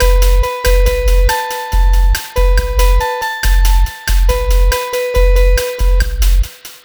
Cheese Lik 140-B.wav